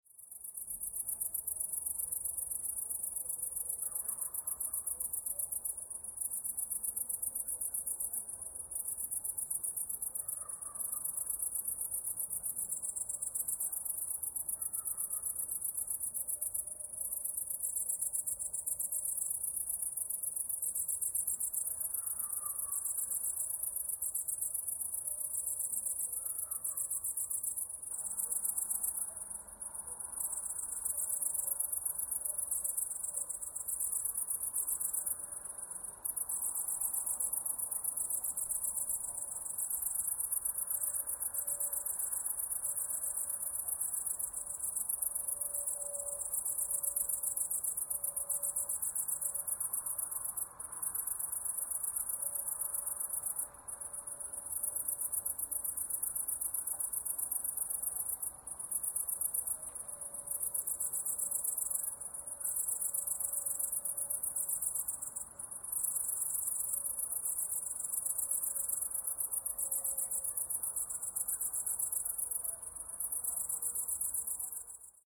Night Insect Sounds in 3D Audio – Relaxing Nature Ambience
High-quality ambisonic sound effect of insects chirping on a warm summer night. Captured with the RODE NT-SF1 ambisonic microphone and Zoom F6 recorder for immersive 3D spatial audio.
Genres: Sound Effects
Night-insect-sounds-in-3d-audio-relaxing-nature-ambience.mp3